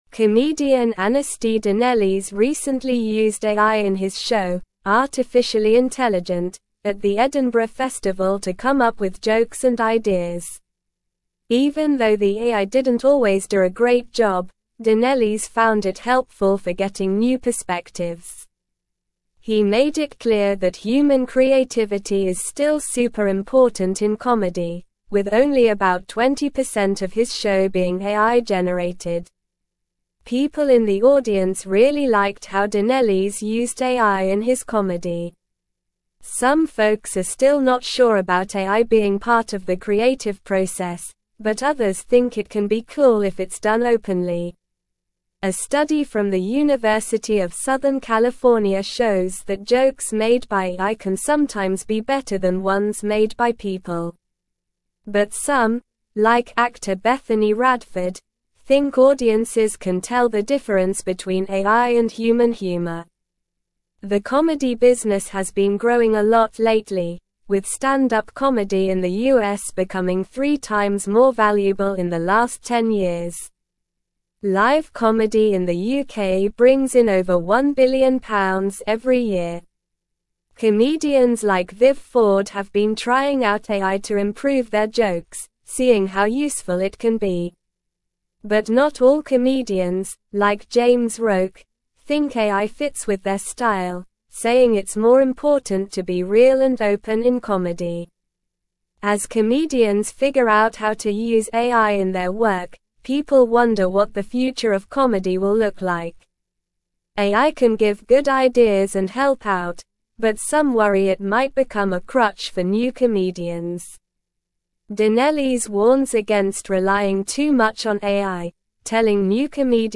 Slow
English-Newsroom-Upper-Intermediate-SLOW-Reading-Comedians-Embrace-AI-for-Joke-Writing-and-Shows.mp3